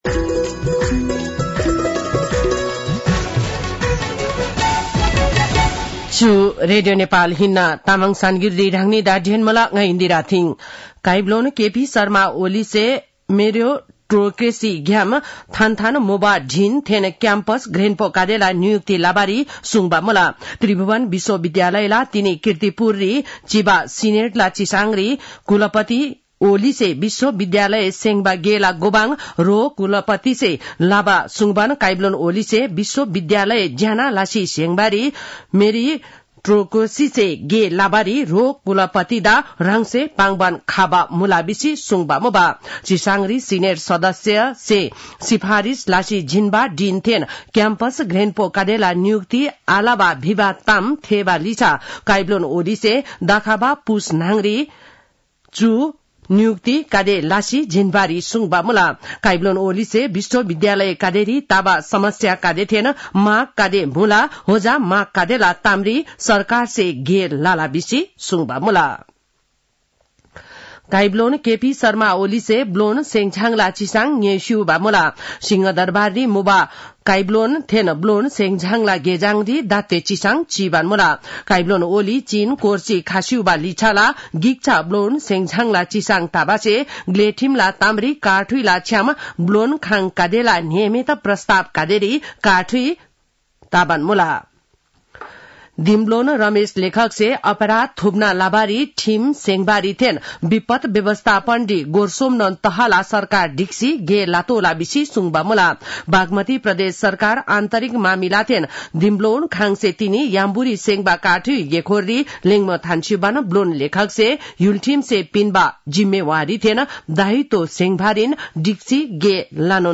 तामाङ भाषाको समाचार : २८ मंसिर , २०८१